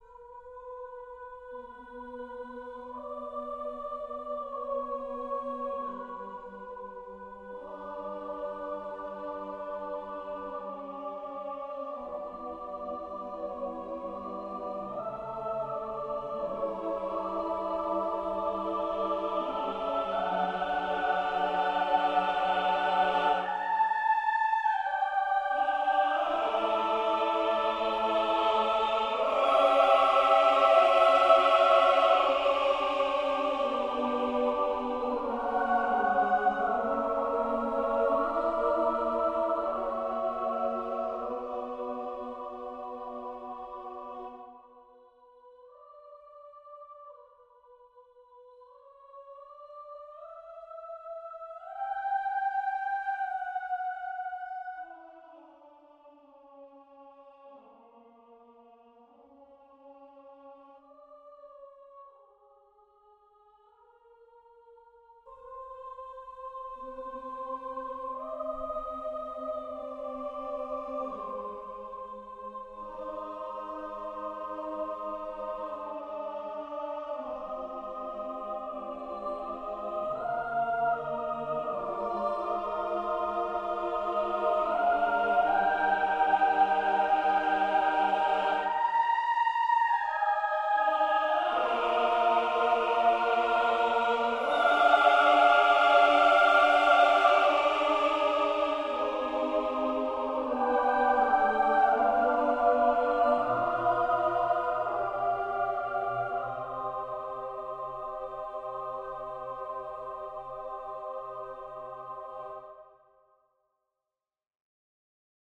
Seven varations for orchestra